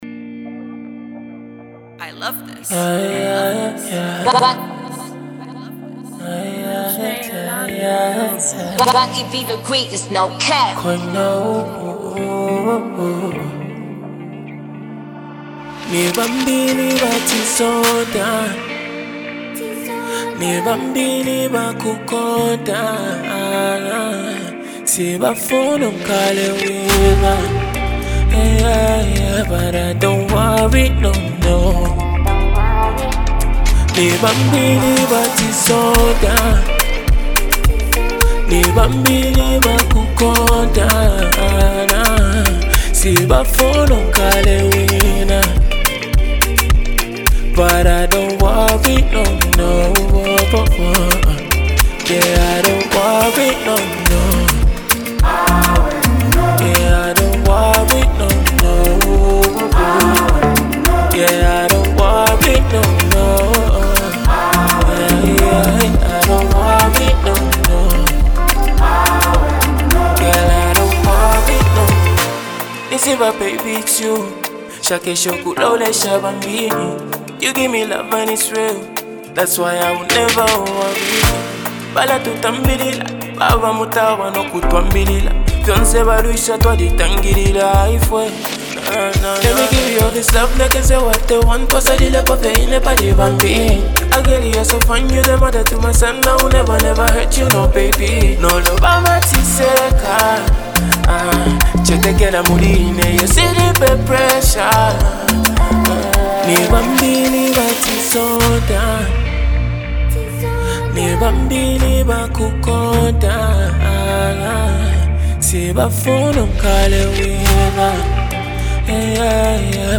” offers a blend of catchy beats and uplifting lyrics